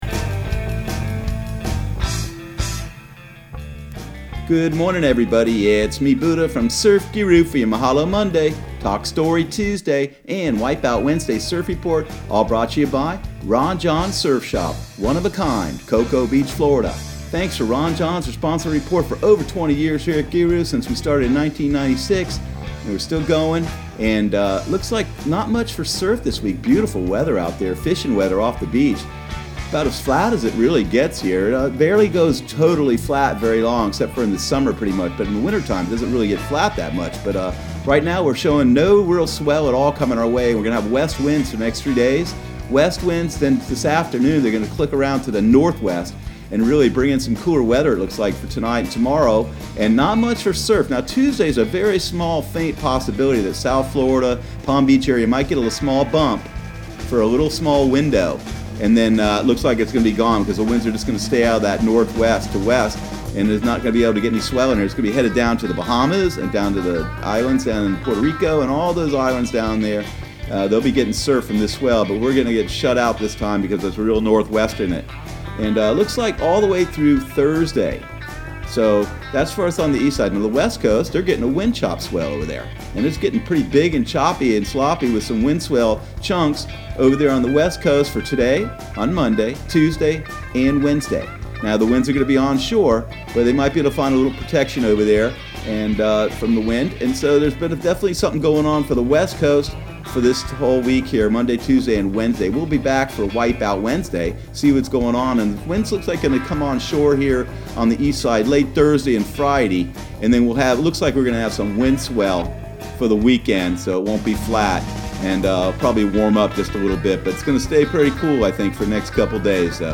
Surf Guru Surf Report and Forecast 12/02/2019 Audio surf report and surf forecast on December 02 for Central Florida and the Southeast.